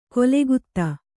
♪ kolegutta